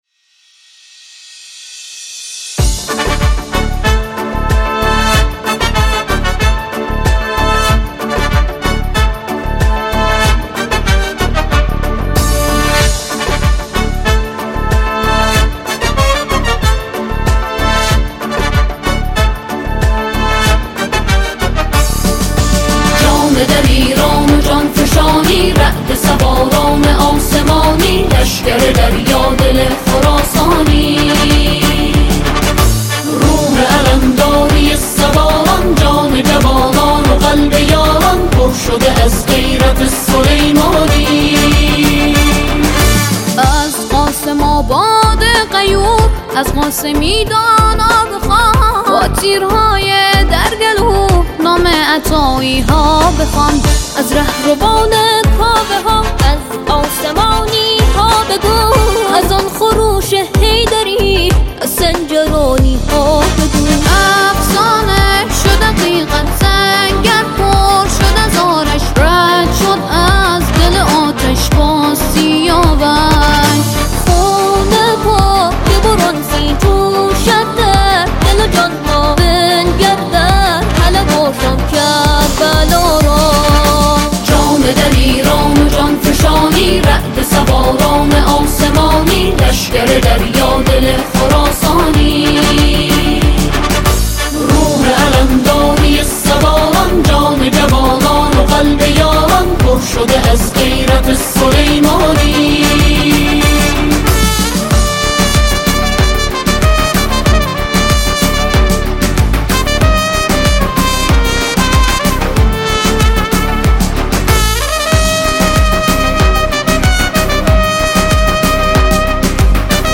سرود ملی، مذهبی، انقلابی و حماسی